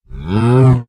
sounds / mob / cow / say1.ogg